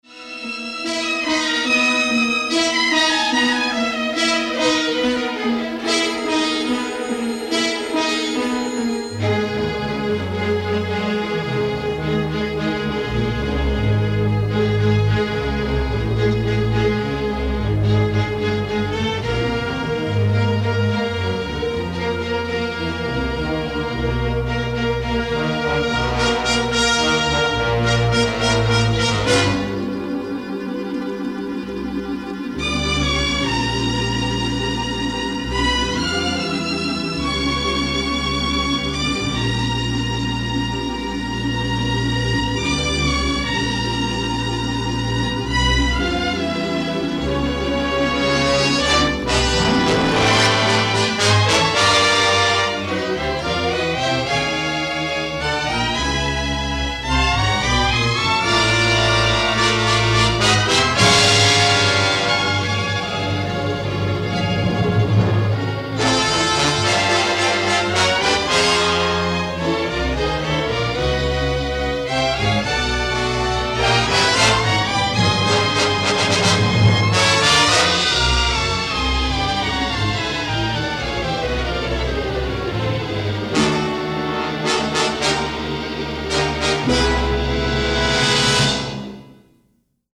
rousing orchestral work